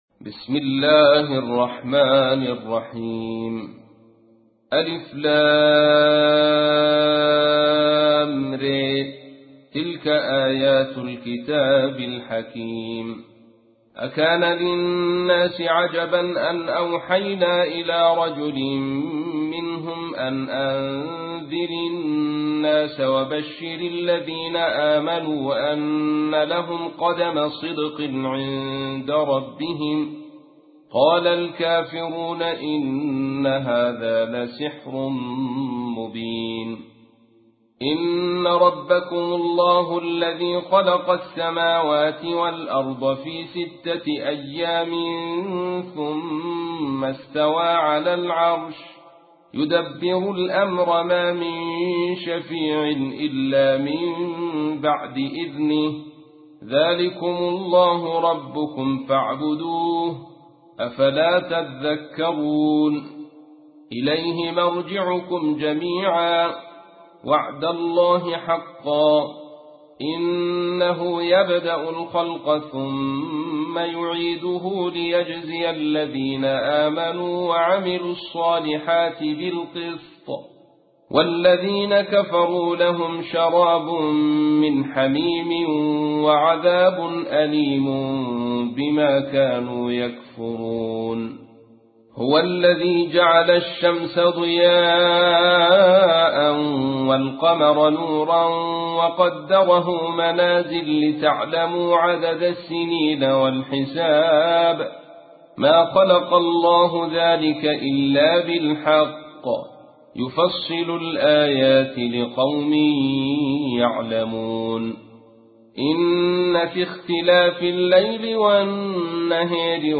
تحميل : 10. سورة يونس / القارئ عبد الرشيد صوفي / القرآن الكريم / موقع يا حسين